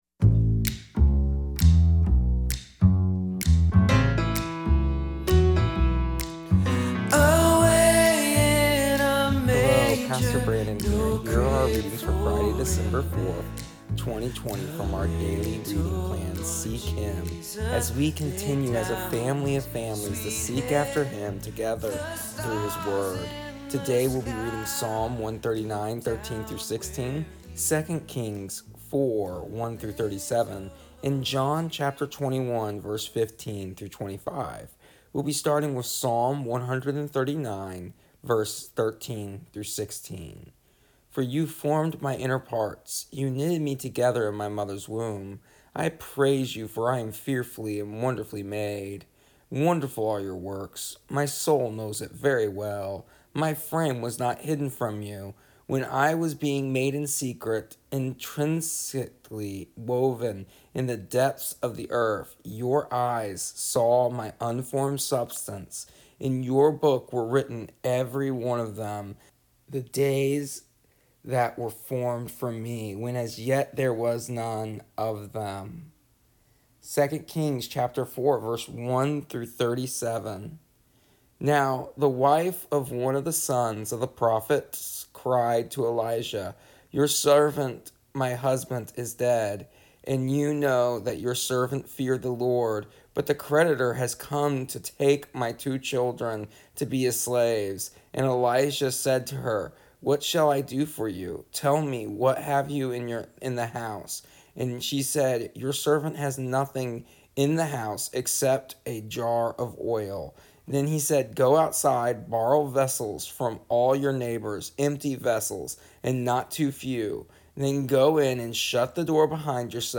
Here is the audio version of our daily readings from our daily reading plan Seek Him for December 4th, 2020, and what wonderful readings we have today about the provision, love, and care of God our father.